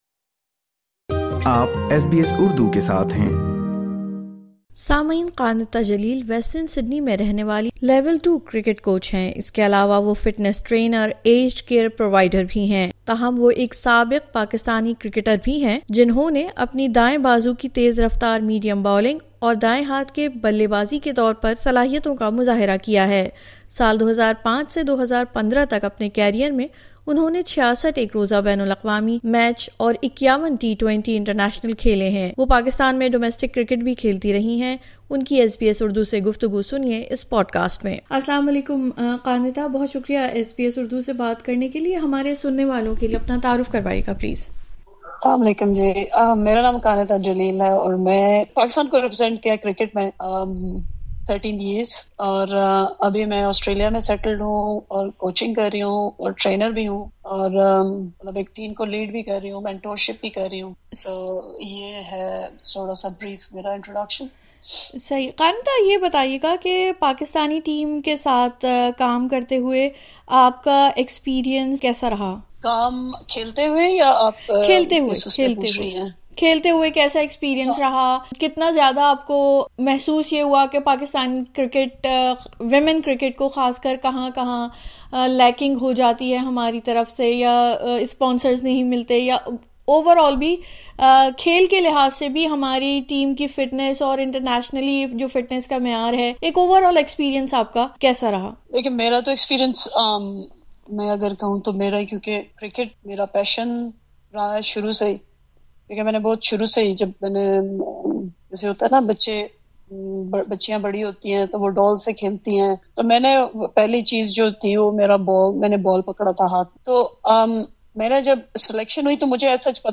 خصوصی گفتگو